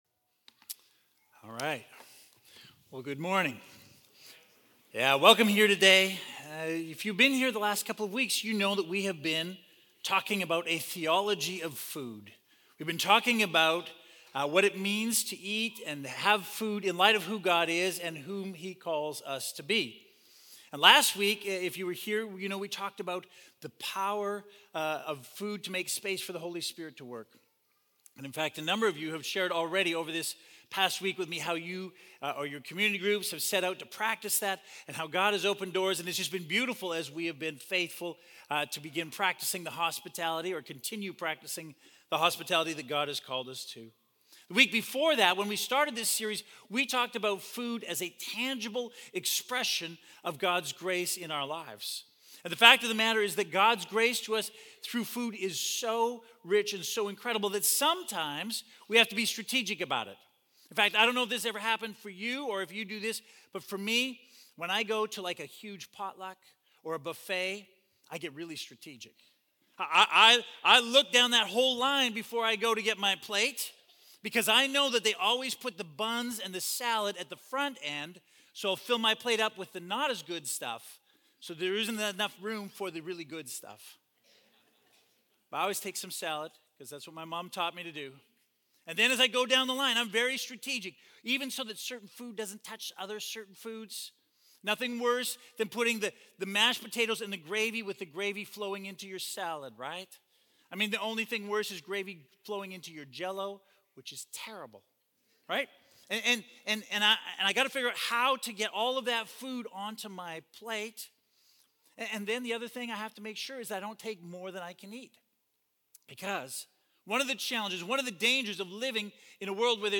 Sermons | Ridge Church